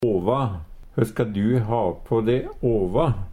åva - Numedalsmål (en-US)